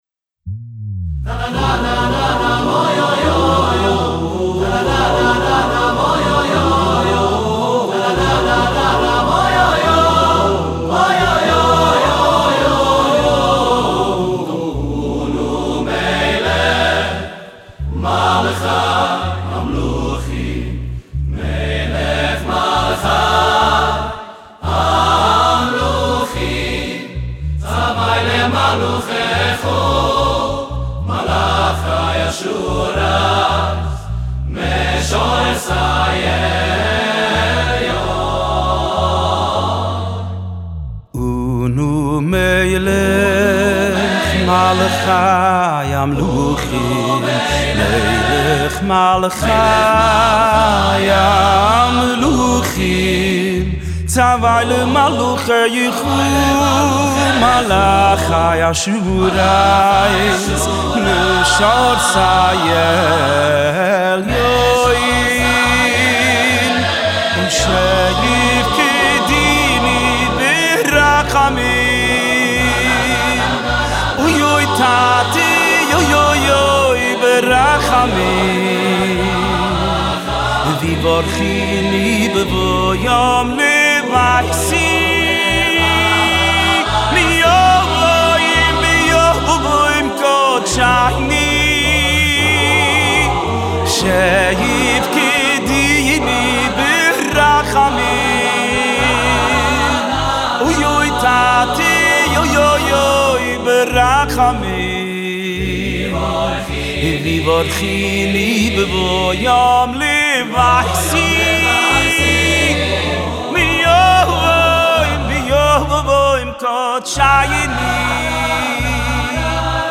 בפרק השני מתוך סדרת הביצועים הווקאליים
עם הרמוניות עשירות וקולות מרהיבים